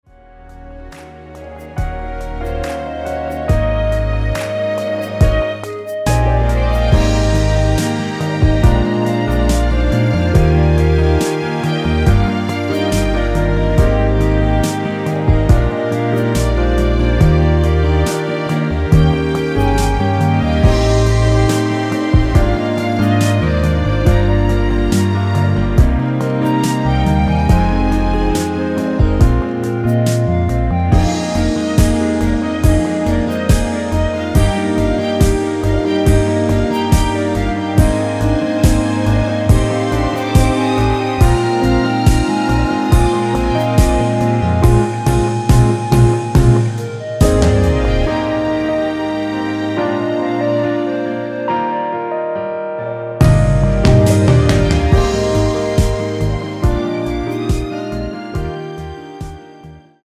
Db
멜로디 MR이라고 합니다.
앞부분30초, 뒷부분30초씩 편집해서 올려 드리고 있습니다.
중간에 음이 끈어지고 다시 나오는 이유는